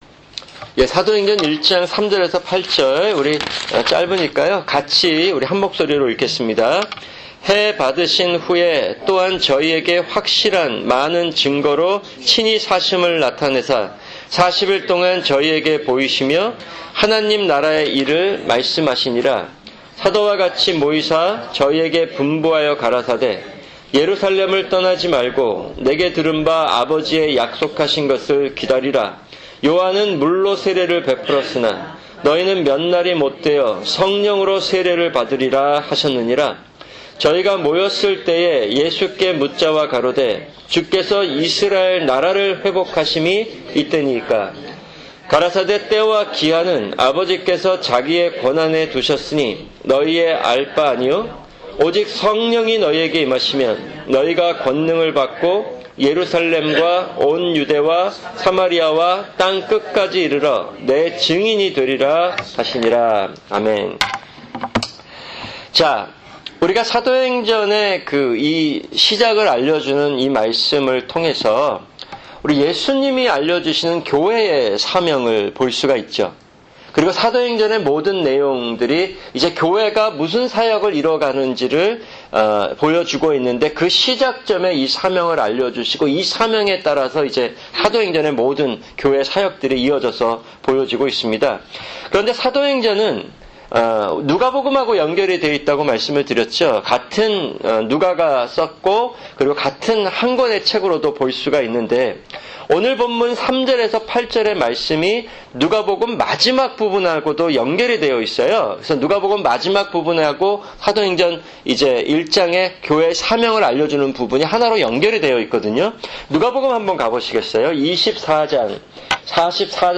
[금요 성경공부] 사도행전1:3-8(1)